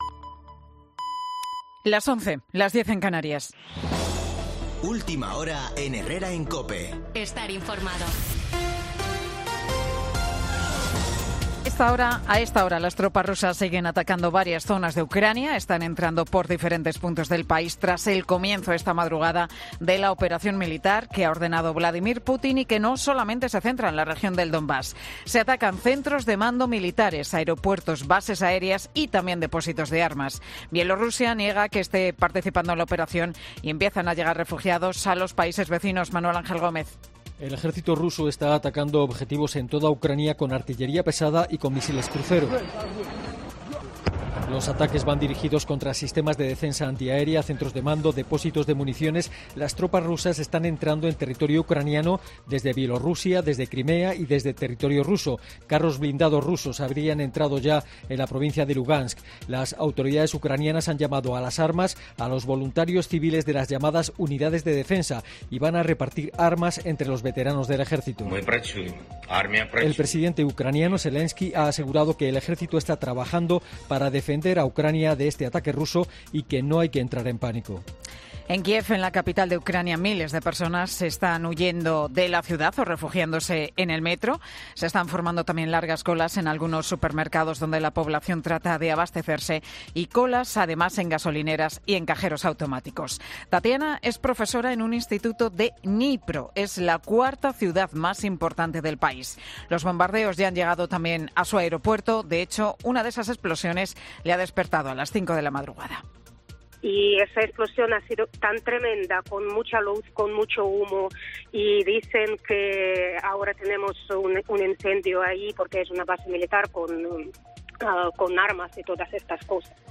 Boletín de noticias de COPE del 24 de febrero de 2022 a las 11.00 horas